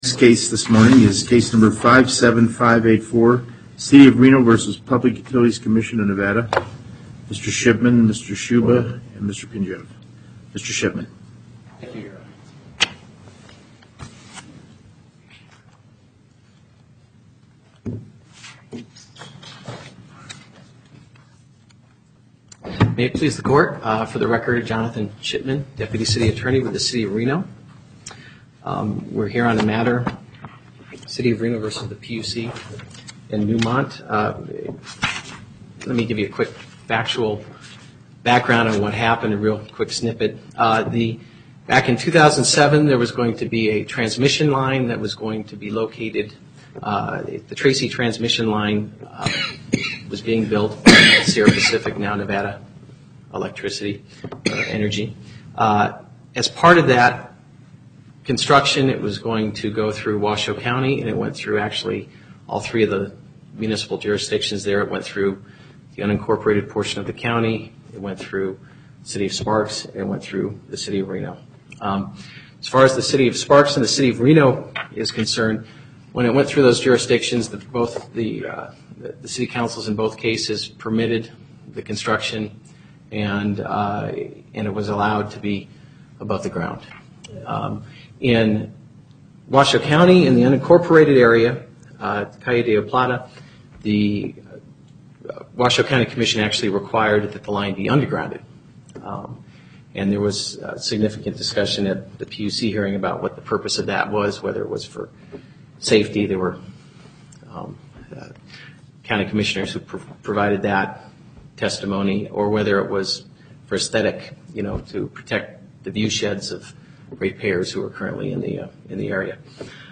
Location: Carson City Northern Nevada Panel, Justice Douglas Presiding